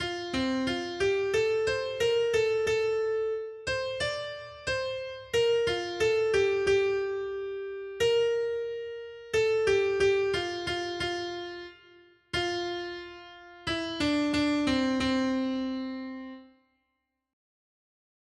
Noty Štítky, zpěvníky ol118.pdf responsoriální žalm Žaltář (Olejník) 118 Ž 104, 1-2 Ž 104, 5-6 Ž 104, 10 Ž 104, 12-14 Ž 104, 24 Ž 104, 35 Skrýt akordy R: Sešli svého ducha, Pane, a obnov tvářnost země. 1.